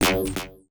UIClick_Laser Double Impact 04.wav